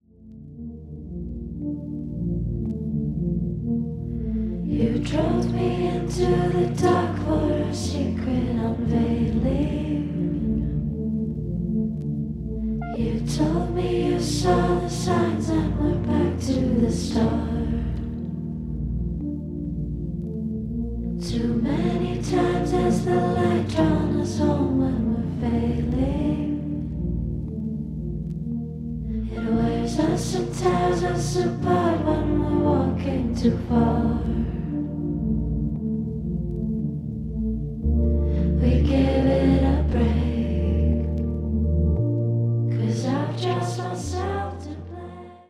水の中を漂うような浮遊感だったり、音の隙間であったり、ドラマティック過ぎない展開であったりが、疲れた身体に染みわたる。